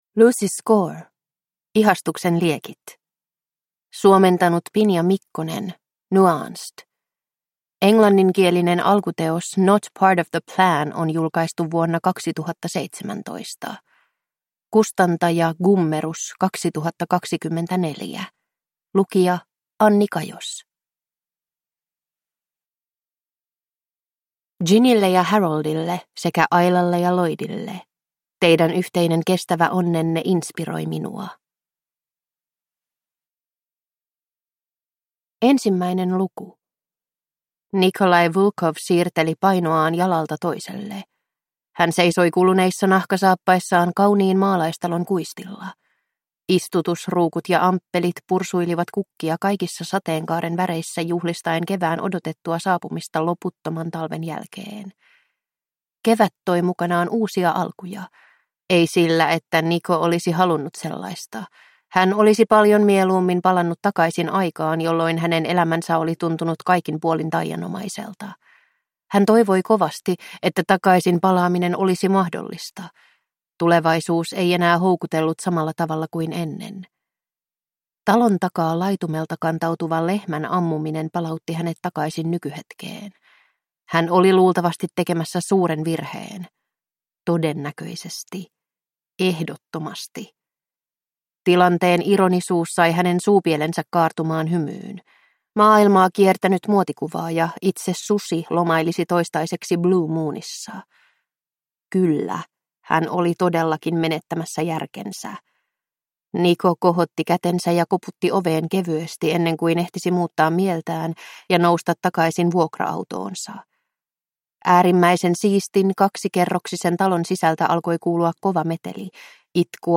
Ihastuksen liekit (ljudbok) av Lucy Score